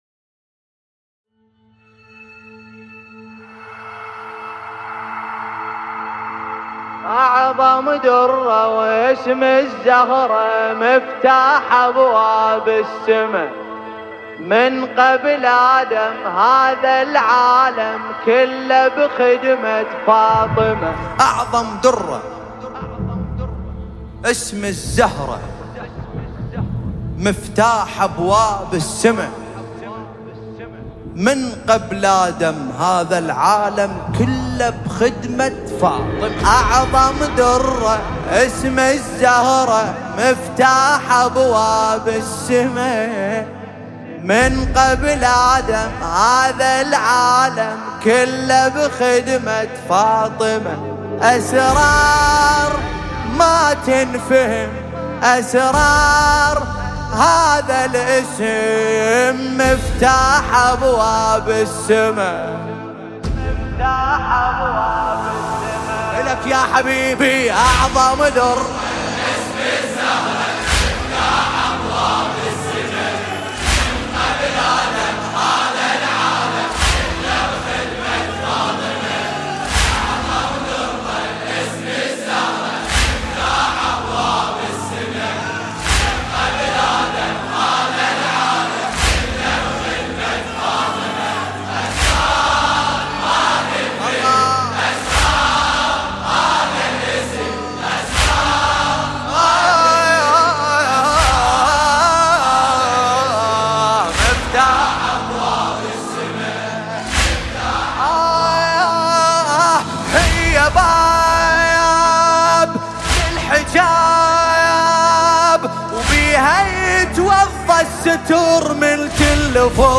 نوحه عربی دلنشین